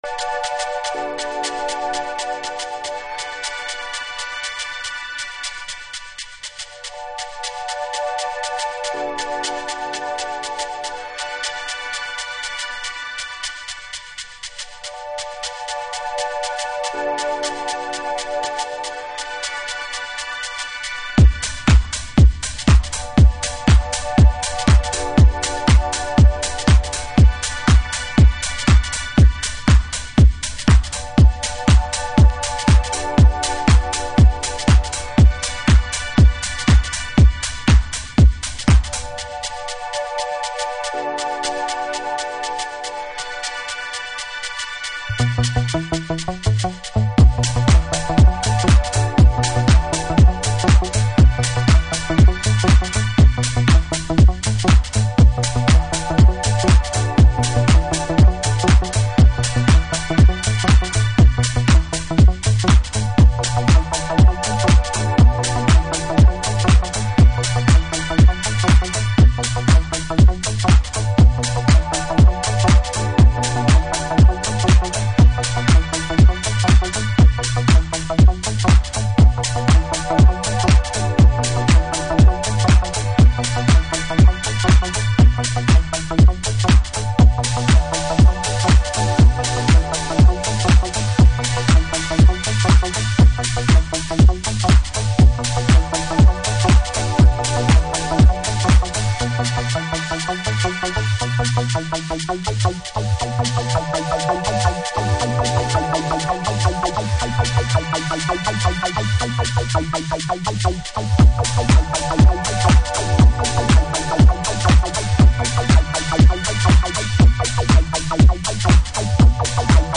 イタロを経た、特有の低域の重み、詫び錆びベースラインとヒプノティックなシンセでトランスさせてくれるトラックです。